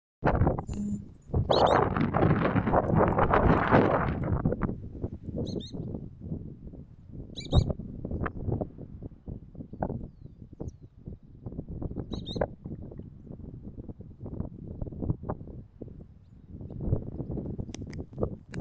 Hudsonian Godwit (Limosa haemastica)
Location or protected area: Reserva Natural Punta Rasa
Condition: Wild
Certainty: Observed, Recorded vocal